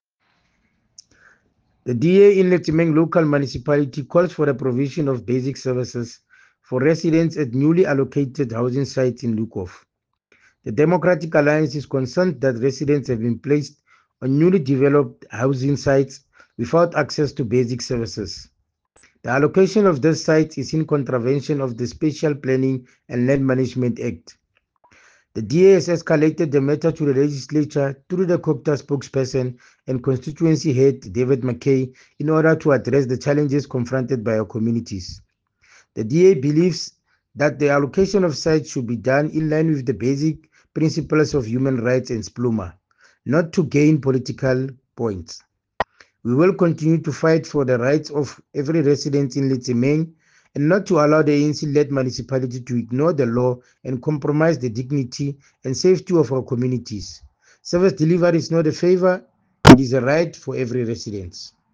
English soundbite by Cllr Thabo Nthapo and Sesotho soundbite by Jafta Mokoena MPL